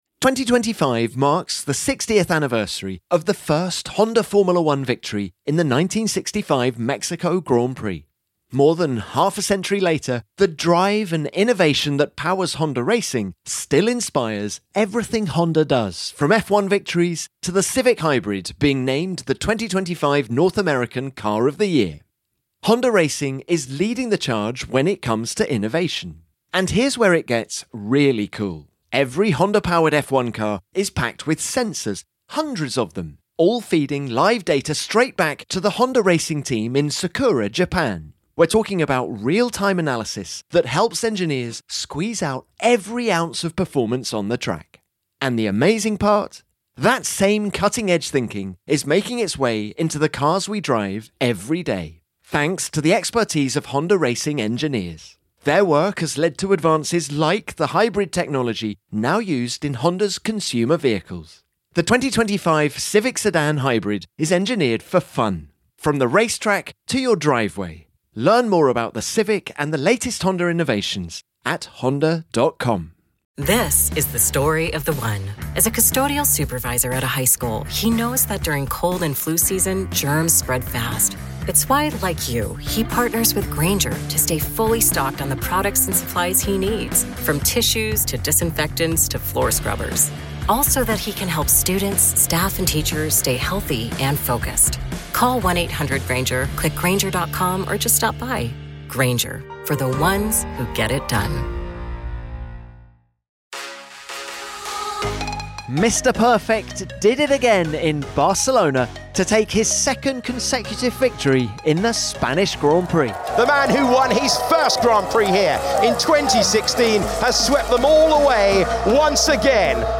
You’ll hear what Lewis Hamilton thinks about their upgrades, after he finished P2 and George Russell stormed from 12th to third.
Plus, Esteban Ocon joins the pod after following his Monaco podium with a solid P8.